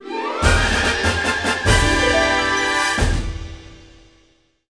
SFX胜利音效下载
SFX音效